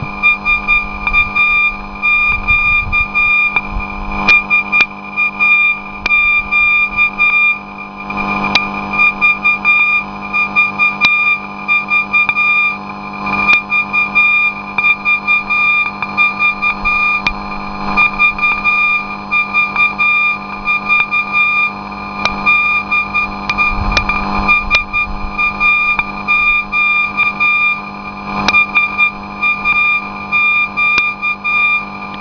Op 29/06/2008 was er weer een uitzending van SAQ, de machinezender uit Zweden.
Ditmaal wou ik het op deze manier proberen te ontvangen: de raam-antenne die ik in 2006 bezigde met 110 windingen op de houten kader van 1m x 1m.
Wat betreft de ontvangst was dit het experiment met de beste resultaten, uitgezonderd de storingen die ik kreeg van de schrikdraad in de weide van mijn overburen.